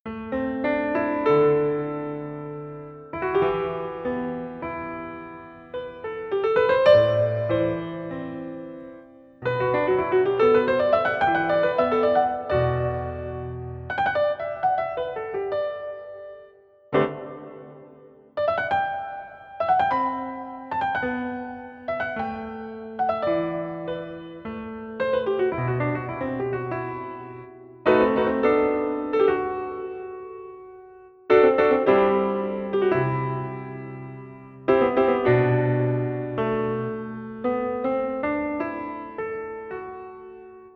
Piano contemporáneo (bucle)
contemporáneo
melodía